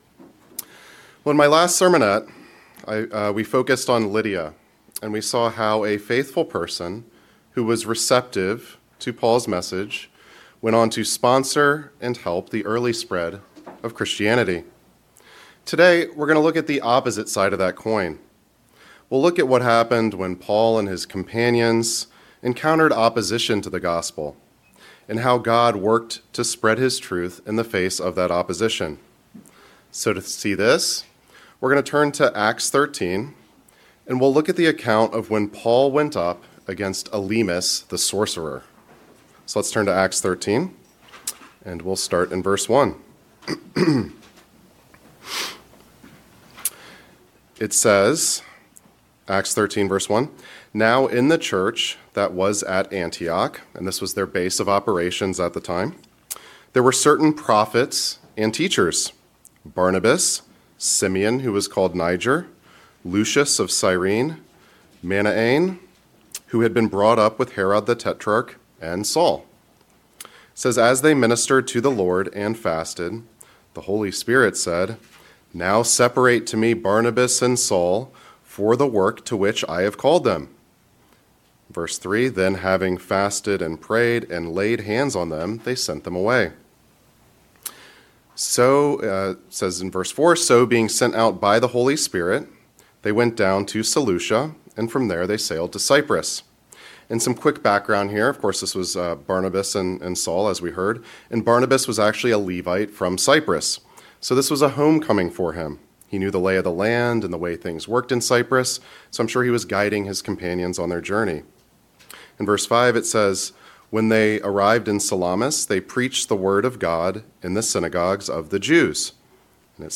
This sermonette looks at Paul’s encounter with Elymas in Acts 13 to show how God advances His purpose when the gospel is opposed. It encourages believers to trust that God reveals truth, removes obstacles, and causes His word to prevail.
Given in Northern Virginia